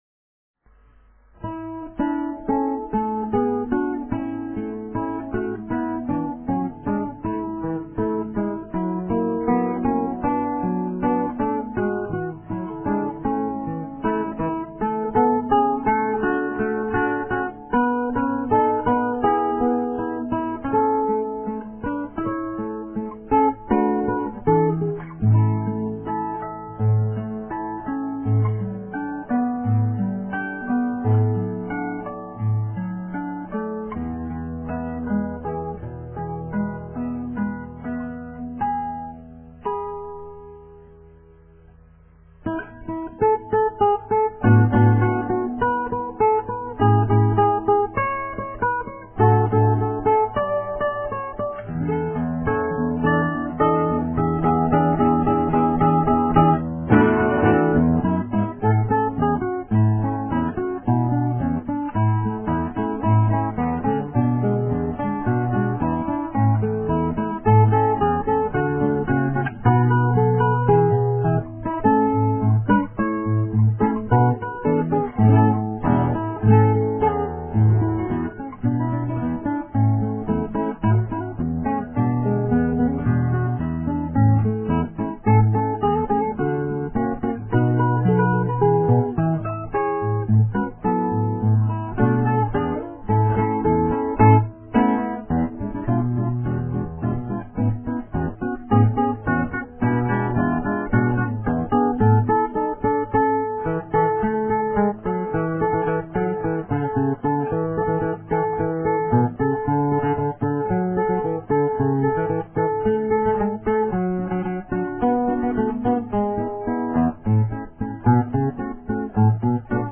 fantasia